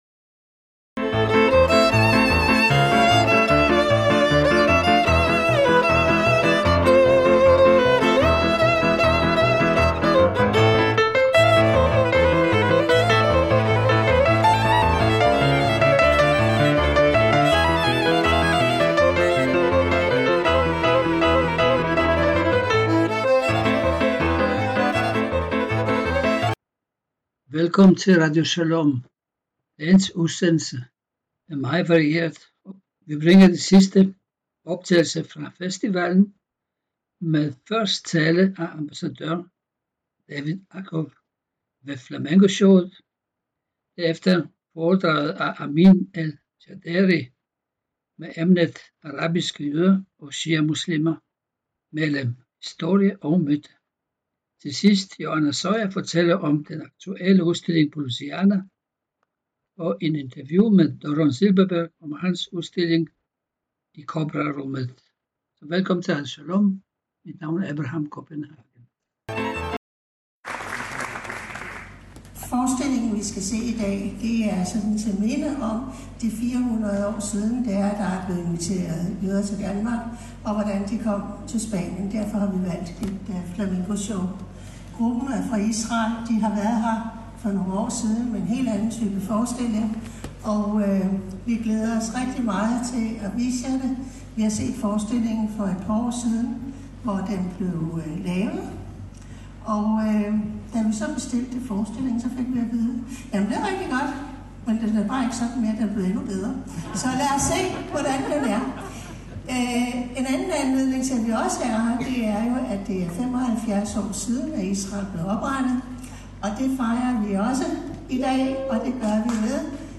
Foredrag – Radioshalom